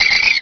Cri de Posipi dans Pokémon Rubis et Saphir.